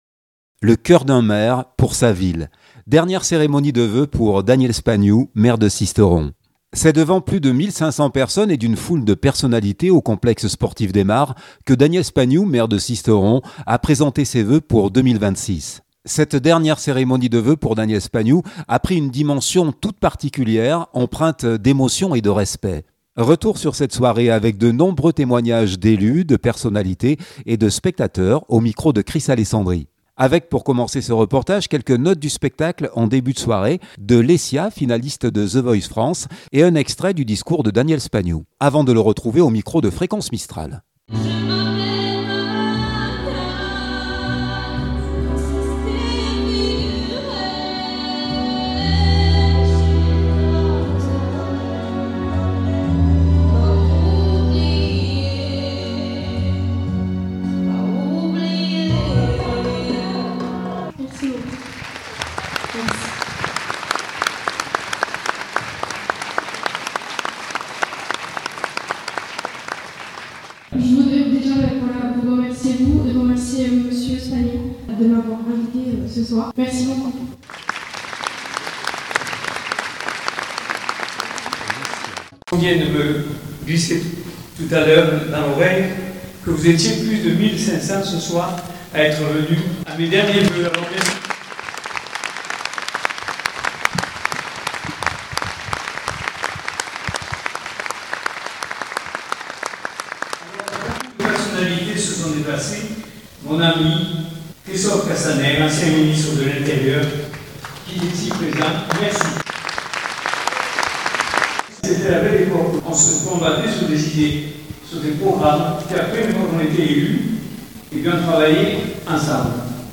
2026-01-08 - Sisteron-Voeux de Daniel Spagnou 2026.mp3 (20.24 Mo) C’est devant plus de 1500 personnes et d’une foule de personnalités au complexe sportif des Marres , que Daniel Spagnou, maire de Sisteron, a présenté ses vœux pour 2026.
Retour sur cette soirée avec de nombreux témoignages d’élus de personnalité et de spectateurs